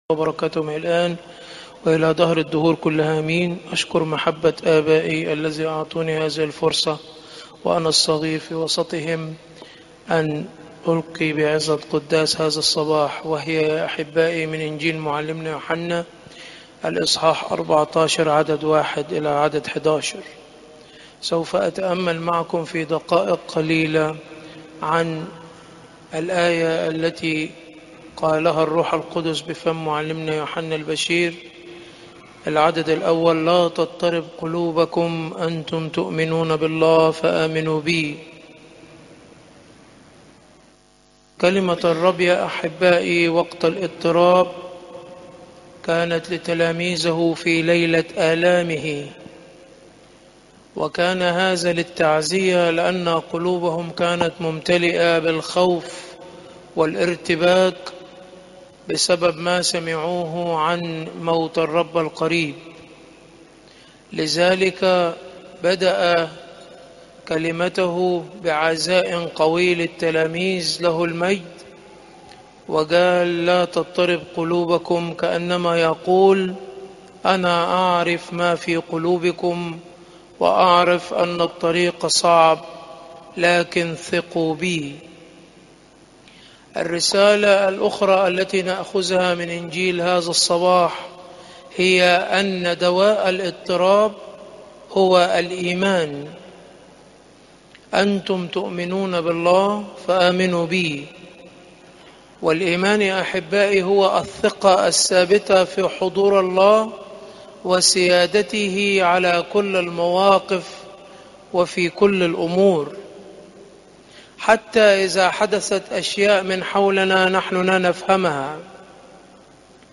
Popup Player تحميل الصوت تحميل الفيديو السبت، 24 مايو 2025 07:26 عظات قداسات الكنيسة (يو 14 : 1 - 11) اليوم السادس الاسبوع الخامس الخمسين المقدسة الزيارات: 315